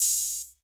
MB Open Hat (3).wav